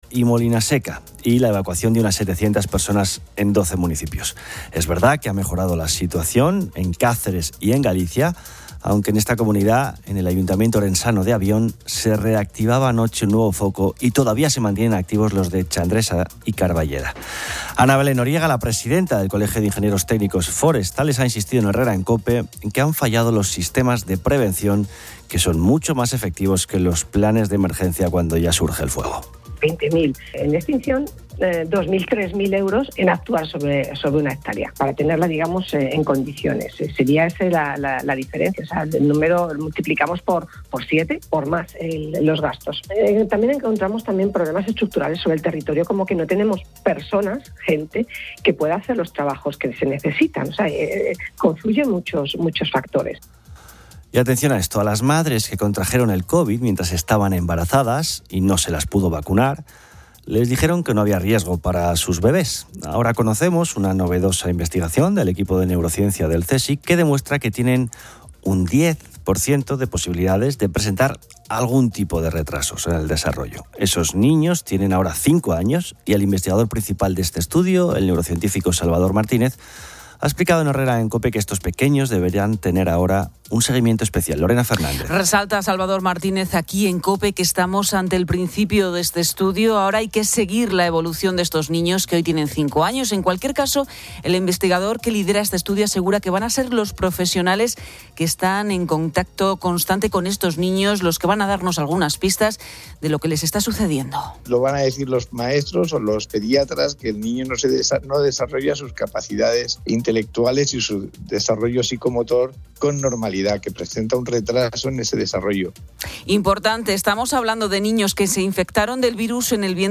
El tema central del día son los "placeres veraniegos", con oyentes compartiendo actividades sencillas como leer al amanecer o disfrutar de bebidas frías.